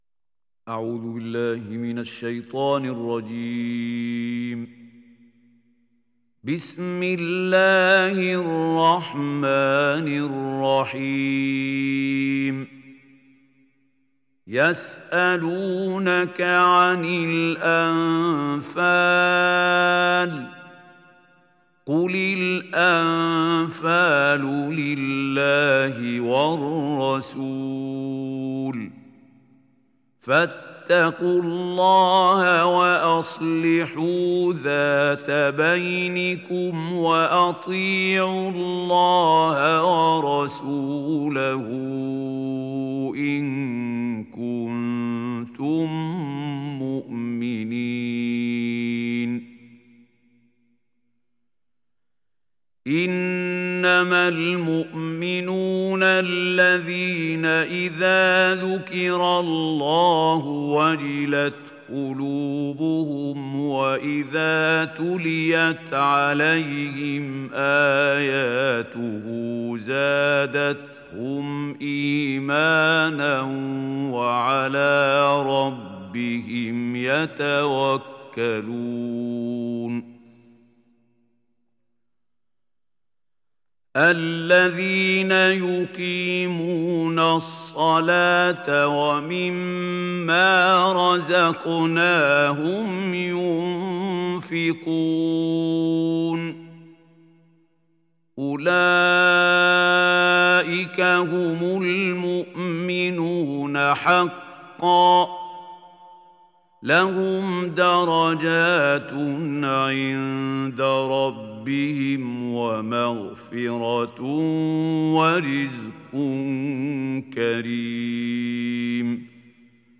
تحميل سورة الأنفال mp3 بصوت محمود خليل الحصري برواية حفص عن عاصم, تحميل استماع القرآن الكريم على الجوال mp3 كاملا بروابط مباشرة وسريعة